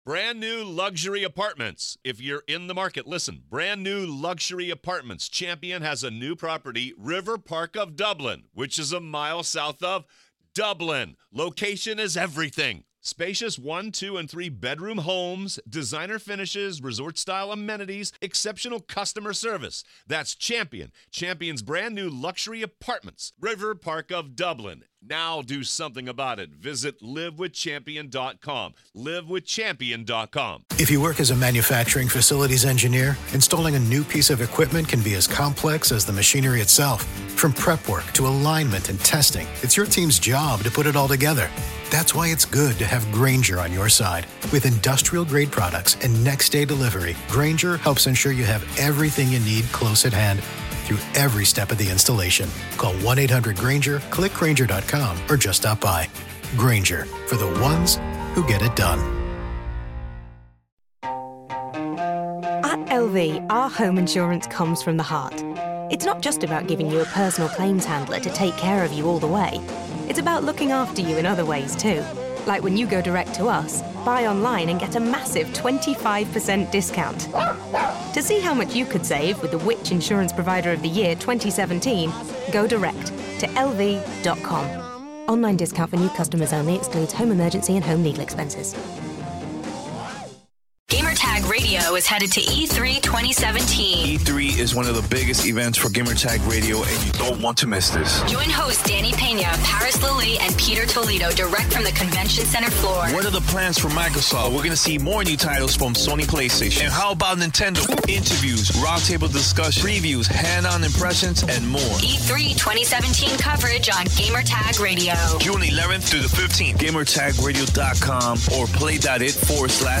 Podcast Interview with Brendan Green about PlayerUnknown's Battlegrounds.